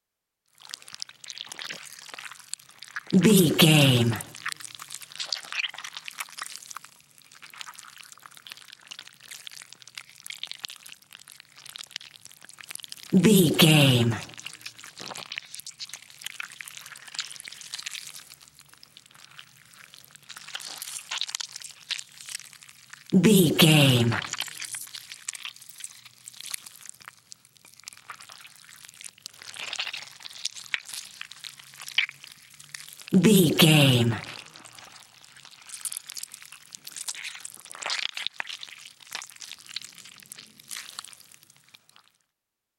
Creature eating flesh juicy chew slow
Sound Effects
scary
disturbing
horror